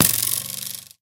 bowhit4.ogg